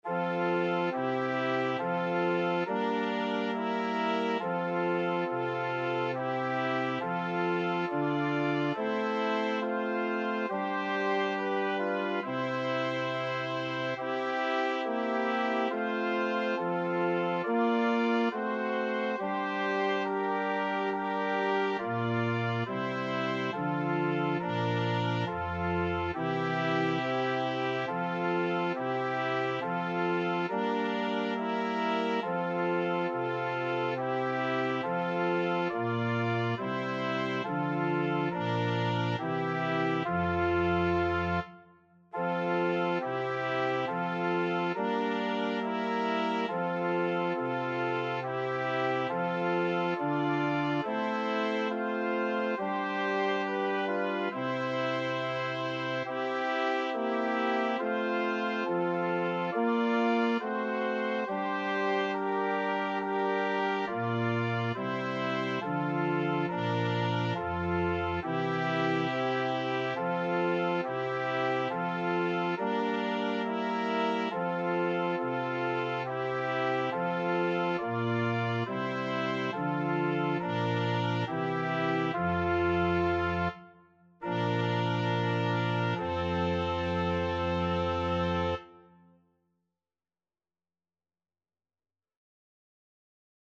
Title: Tantum ergo I Composer: Oreste Ravanello Lyricist: Number of voices: 2, 4vv Voicings: SA, TB or SATB Genre: Sacred, Hymn
Language: Latin Instruments: Organ